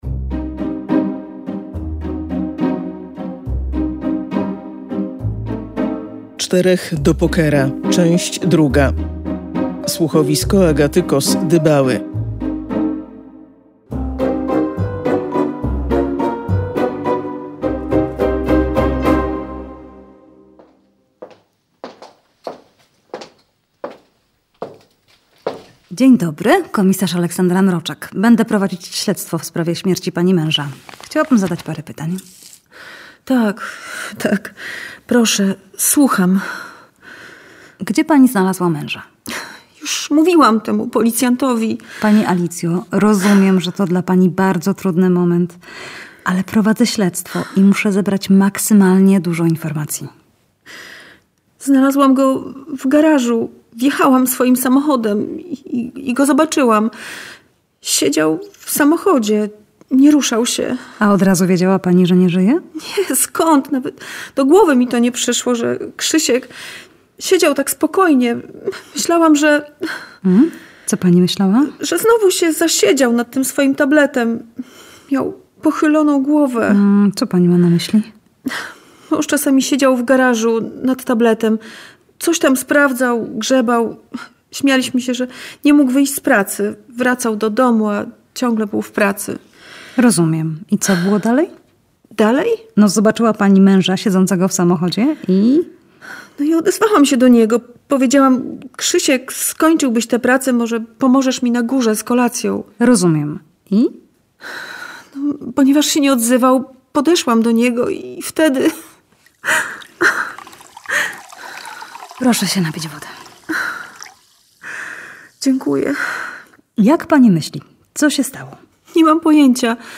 Po 23.00 kolejny drugi odcinek słuchowiska „Czterech do pokera”.